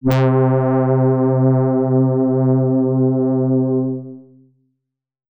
Lush Pad C4.wav